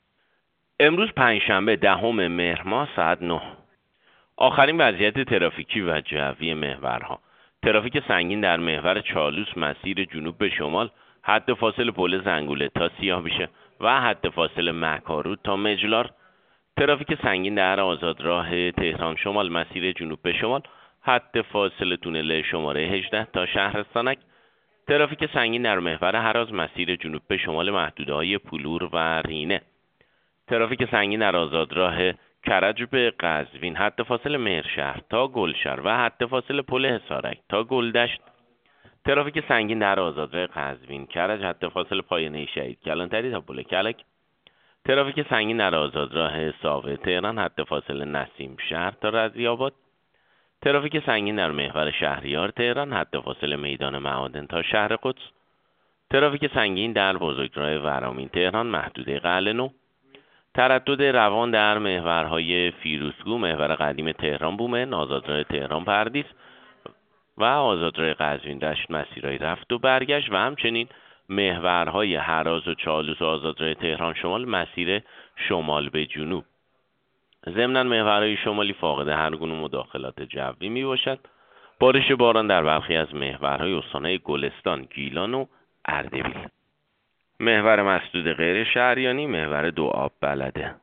گزارش رادیو اینترنتی از آخرین وضعیت ترافیکی جاده‌ها ساعت ۹ دهم مهر؛